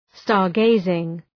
star-gazing.mp3